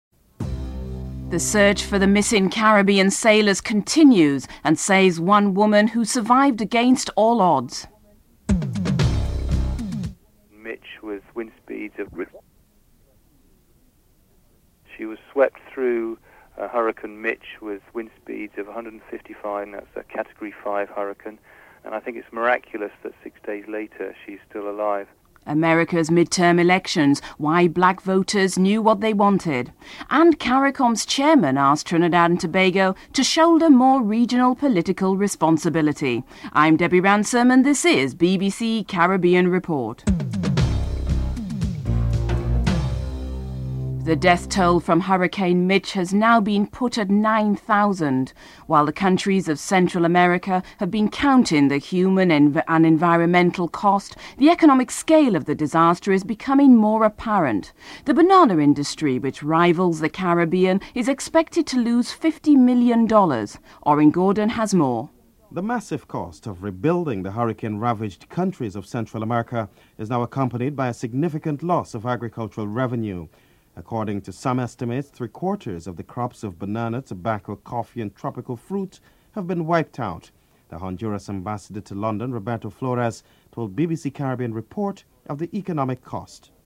The British Broadcasting Corporation
1. Headlines (00:00-00:42)
Black voters are interviewed